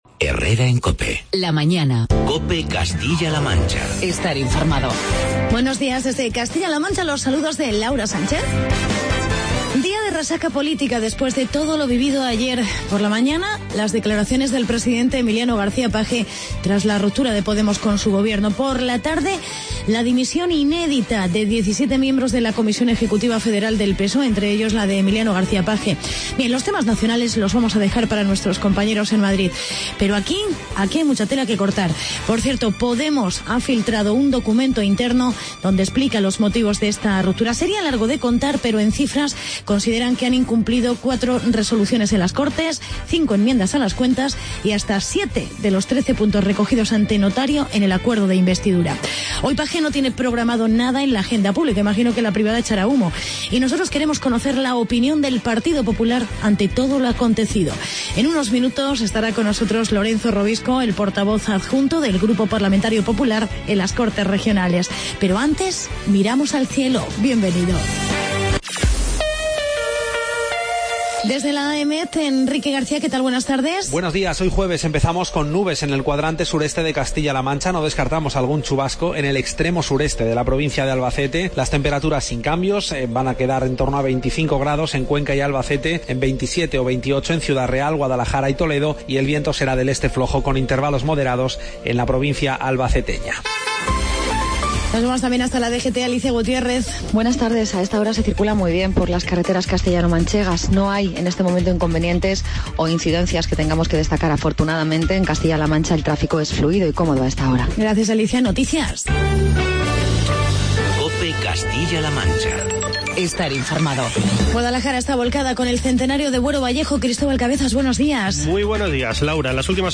Actualidad y entrevista con Lorenzo Robisco, (portavoz adjunto del GPP en las Cortes Regionales), sobre la situación política actual.